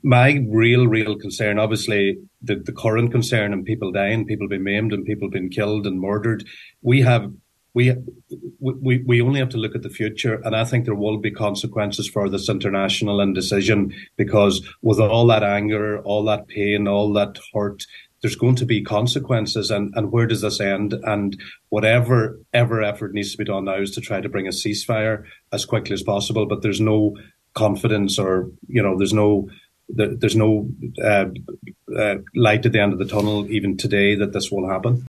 Deputy Joe McHugh, speaking on this morning’s Nine til Noon Show fears the consequences of the ongoing unrest in the Middle East: